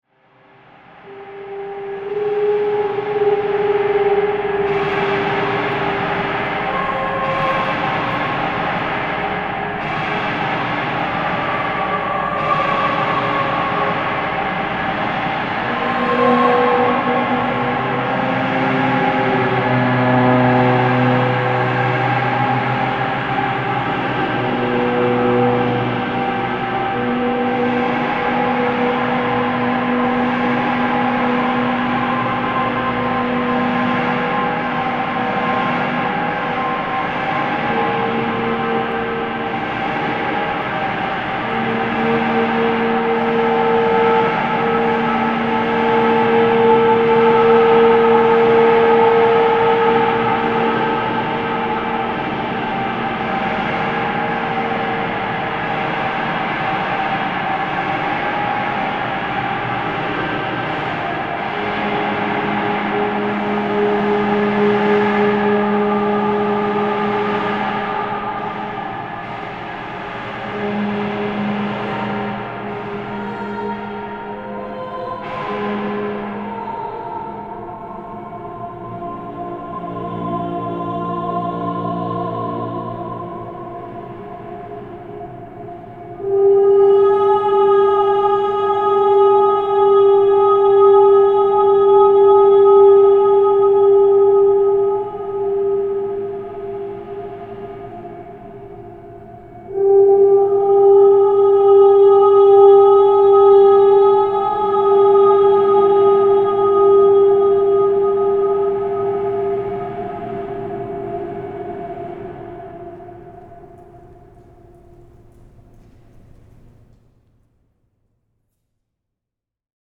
Excerpt from Up Upon The Rhizome at Cantor Arts Center
for soprano, tuba, amplification, fixed media through mono speakers, and a very large space
excerpt-from-rehearsal.mp3